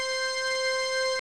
Organ02C.wav